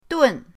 dun4.mp3